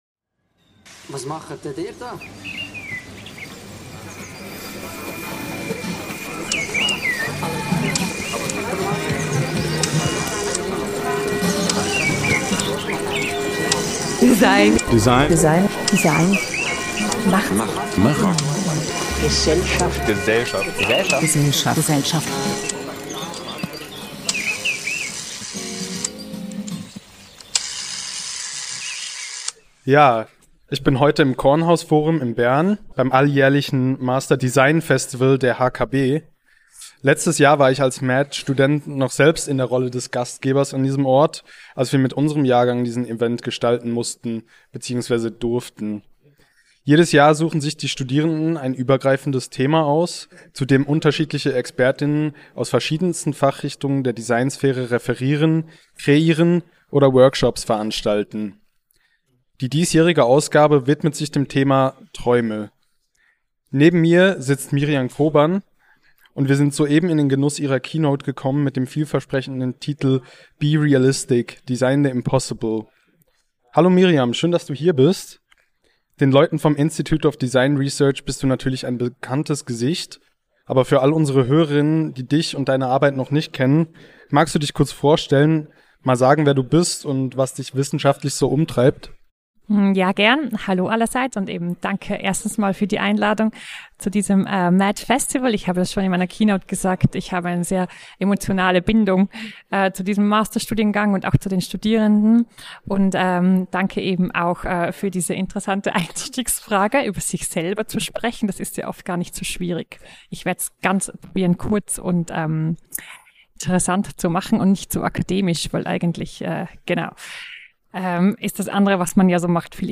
Die Interviews wurden mehrheitlich in englischer Sprache geführt. Disclaimer: Die Tonqualität ist an gewissen Stellen aus technischen Gründen eingeschränkt.